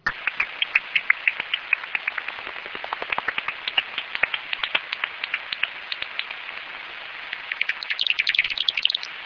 En vol, la pipistrelle commune émet une succession irrégulière de cris d'écholocation en fréquence modulée aplatie aux alentours de 45 kHz, ce qui donne à cette fréquence au détecteur ultrasonique de chauves-souris des bruits "mouillés" comme vous pouvez l'entendre sur ces deux enregistrements:
enregistrement des cris d'écholocation d'une pipistrelle commune (Pettersson D200 réglé sur 45 kHz - 06/09/2008 - Hamois, Belgique)